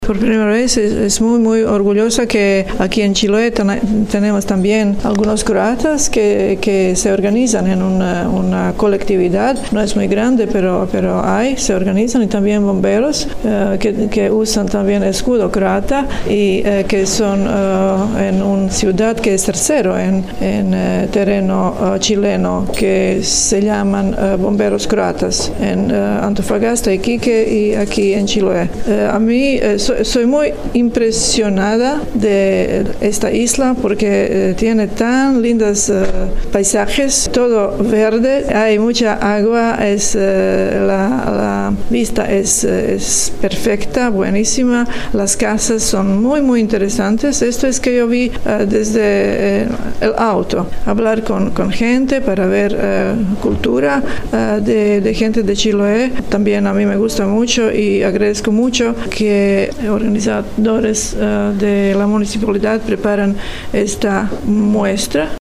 Por su parte, Mira Martinec, embajadora de Croacia se mostró muy impresionada por los paisajes y la artesanía local: